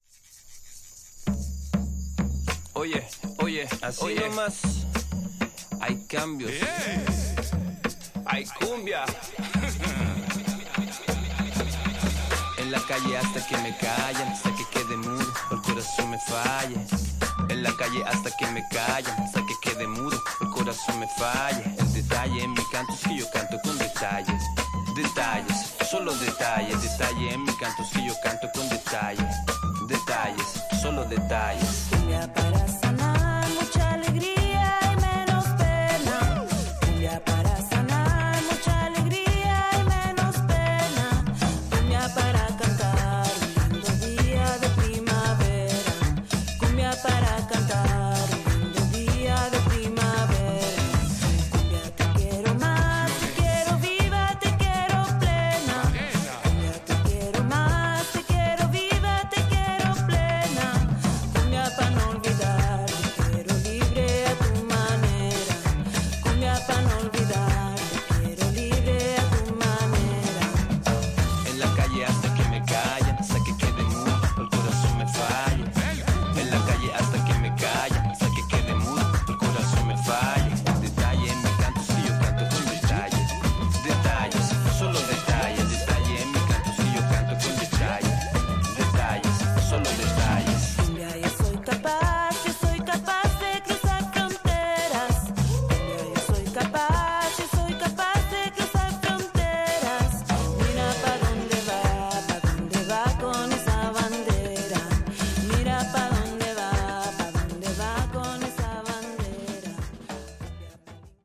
Tags: Cumbia , Champeta , Japan
Two superb dancefloor cumbia / champeta tunes.